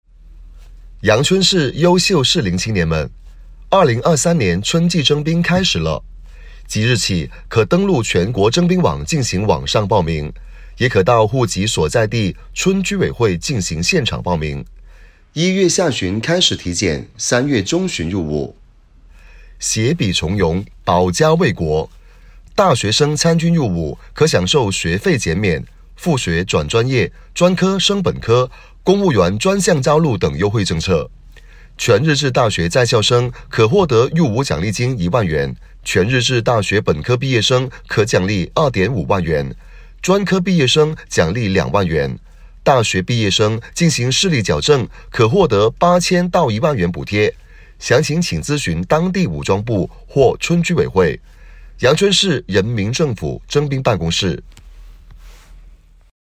阳春市人民政府征兵(有音乐方言)01(1).mp3